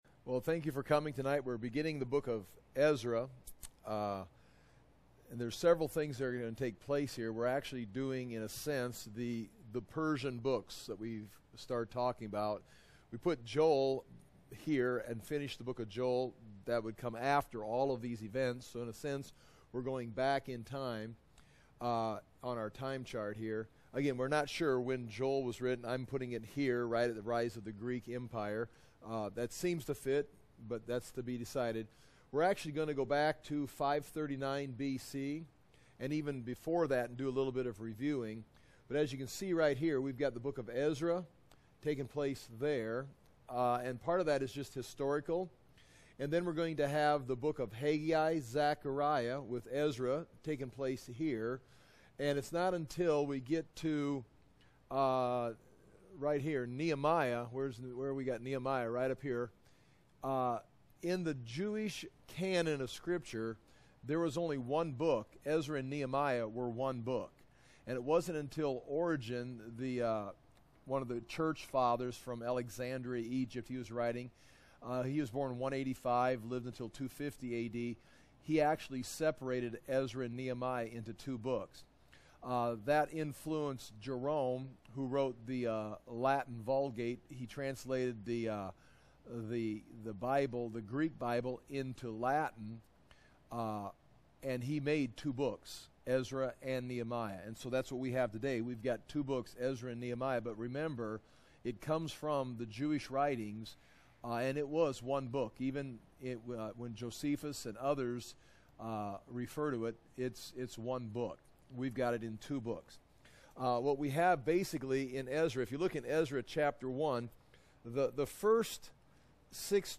Ezra - verse by verse Bible teaching audio .mp3, video, notes, maps, lessons for the Book of Ezra